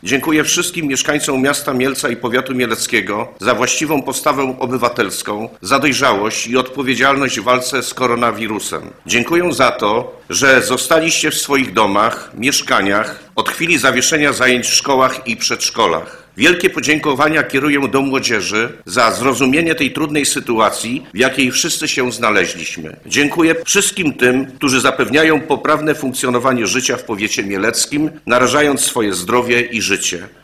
Rada Powiatu Mieleckiego podczas ostatniej skróconej sesji podziękowała za ofiarność i pomoc osobom zaangażowanym w walce z zakażeniem koronawirusem oraz tym, którzy zapewniają poprawne funkcjonowanie życia w powiecie mieleckim narażając swoje zdrowie i życie. Apel odczytał przewodniczący rady powiatu Marek Paprocki.